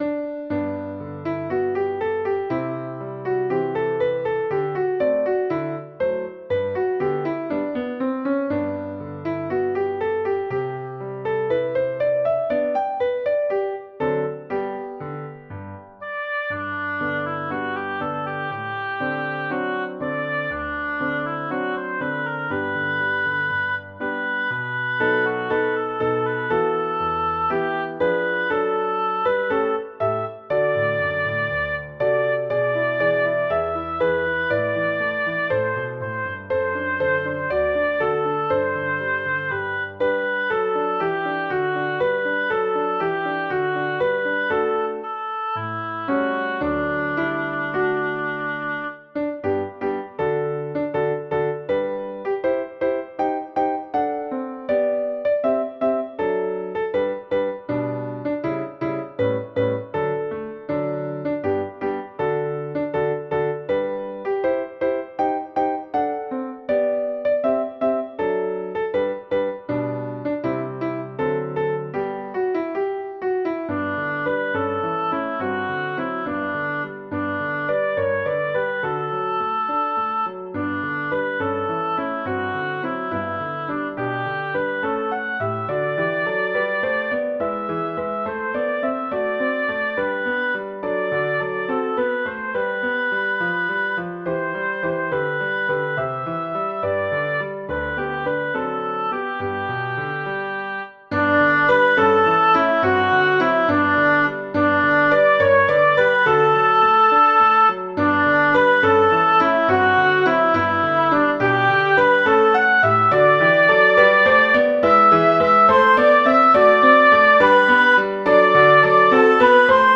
Oboe, piano + extra wind